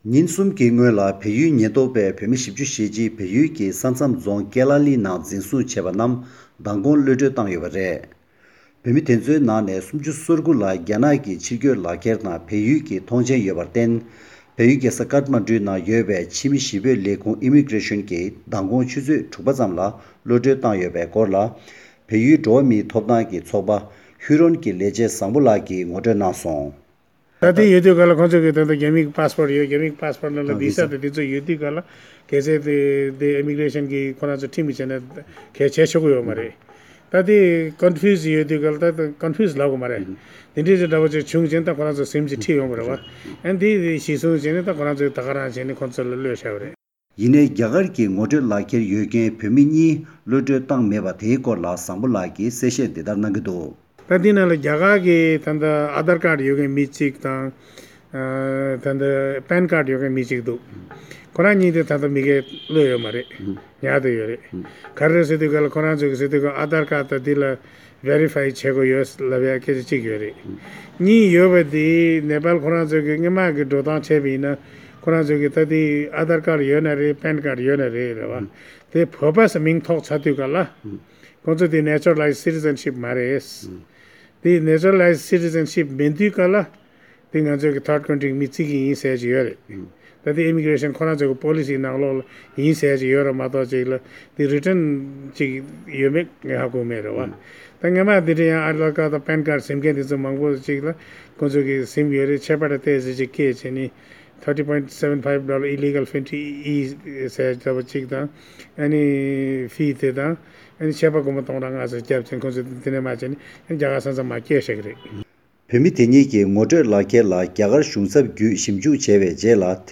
འབྲེལ་ཡོད་གནས་ཚུལ་འདི་གའི་བལ་ཡུལ་གསར་འགོད་པས་བཏང་བར་གསན་རོགས་ཞུ༎
སྒྲ་ལྡན་གསར་འགྱུར།